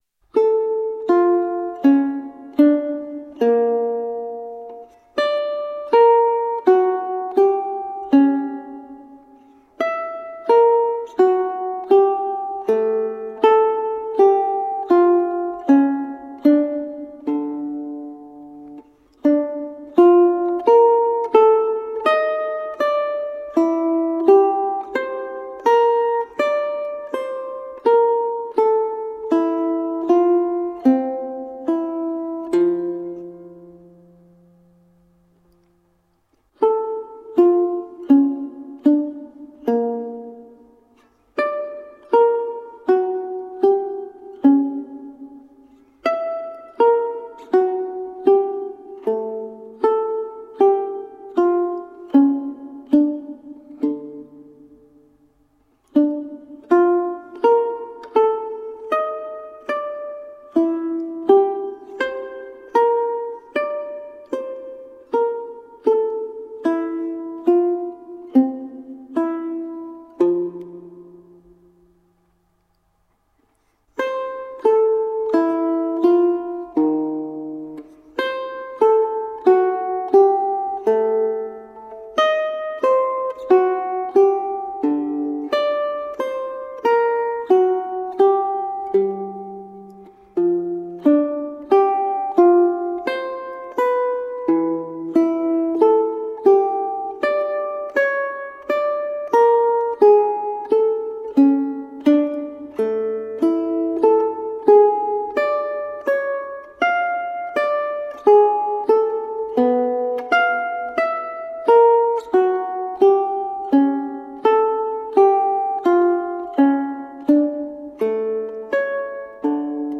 Colorful classical guitar.